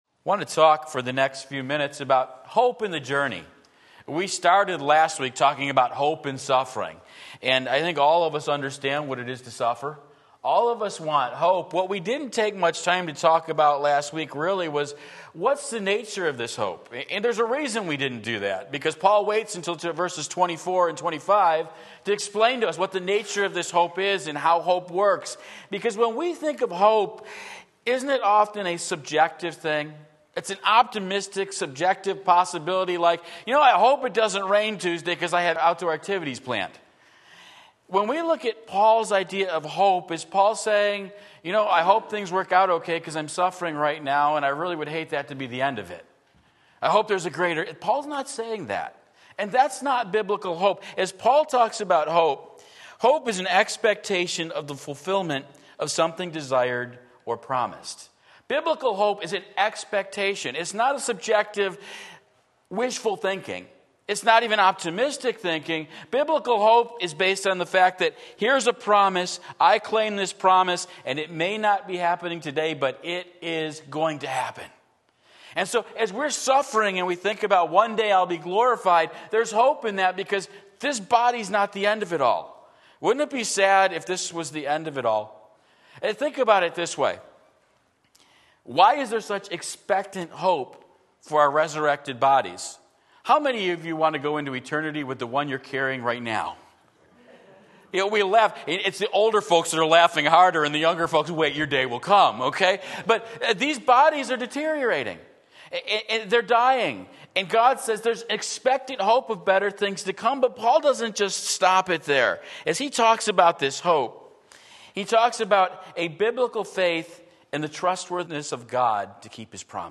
Sermon Link
Part 1 Romans 8:24-27 Sunday Morning Service